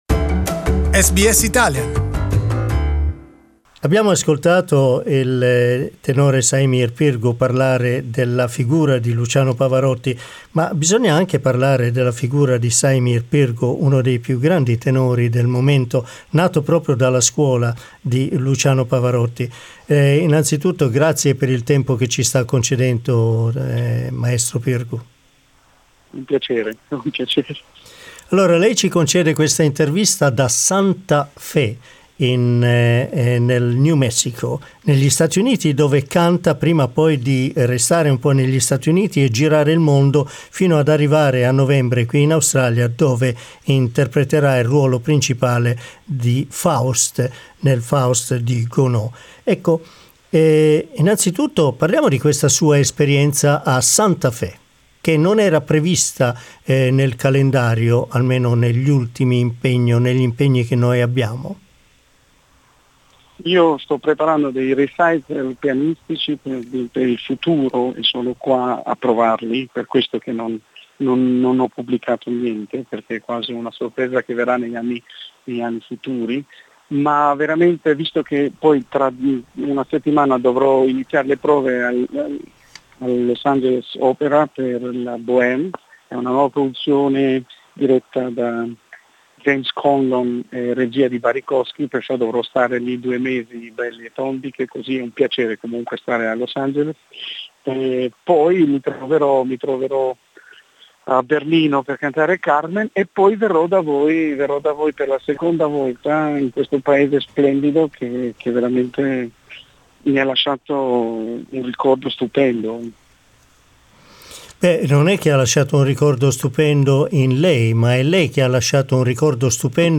SBS Italian caught up with him and asked about his forthcoming engagement with OA, his current busy schedule and his career.